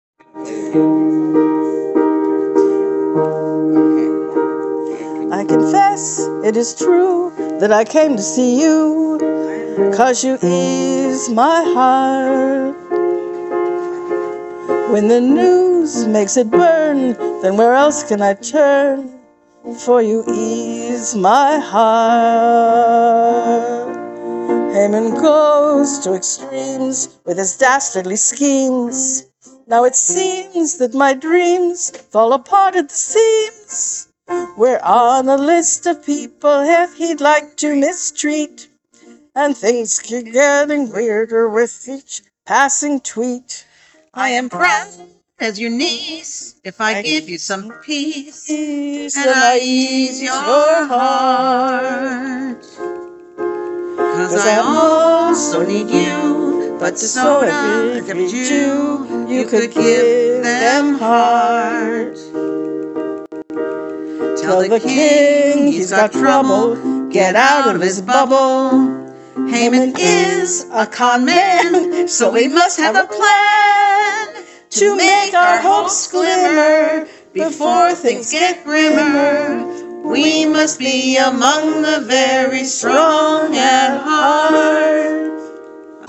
Rough recordings.
Tempos on some things could get faster when we are more familiar.